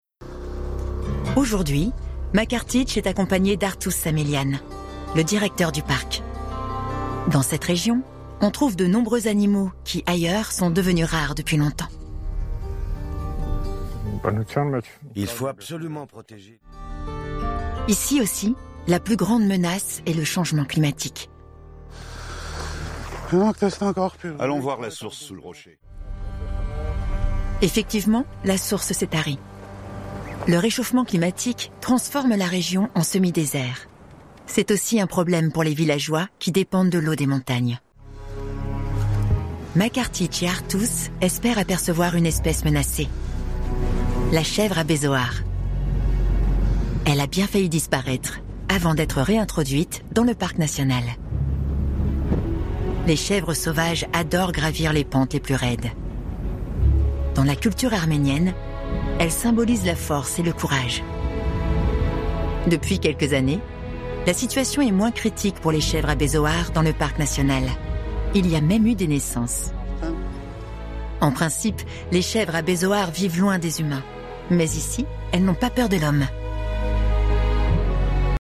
Archives des Droite / Reportage
Voix-off féminine pro, voix fraiches, mutines, complices ou chantantes.